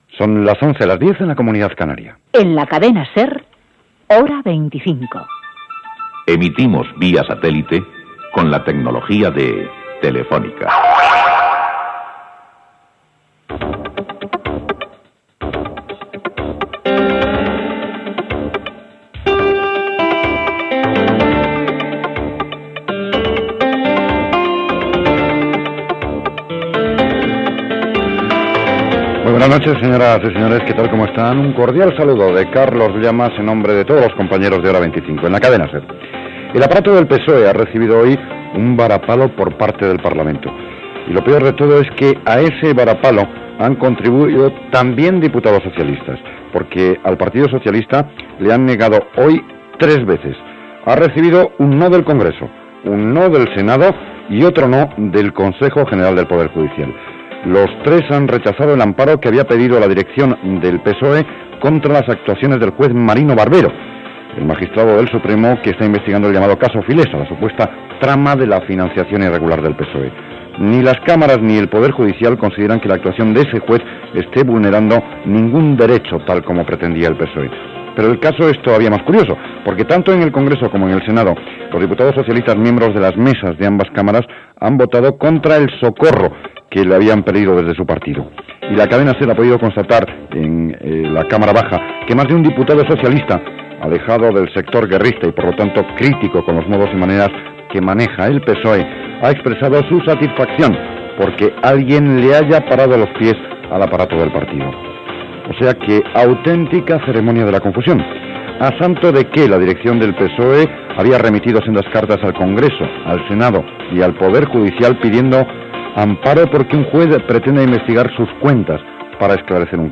Hora, identificació, publicitat, no del Congrés, el Senat i el poder Judicial al PSOE demanant empara pel cas Filesa , titulars.
Informatiu